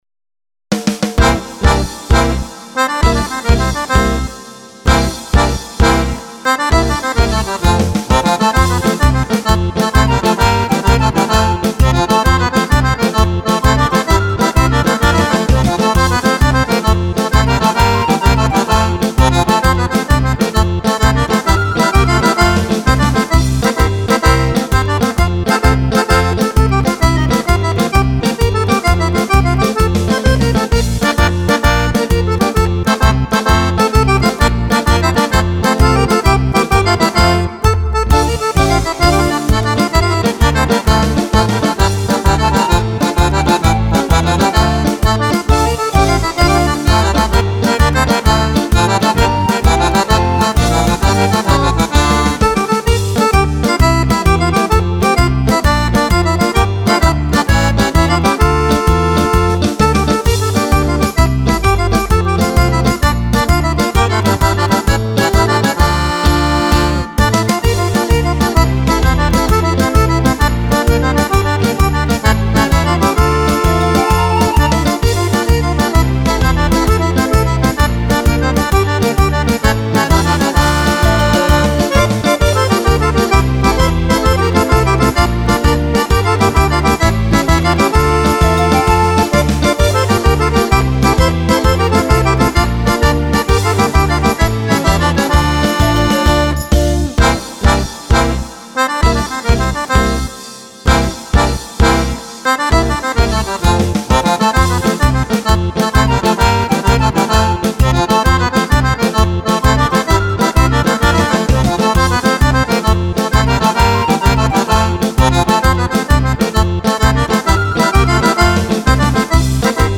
(Tarantella)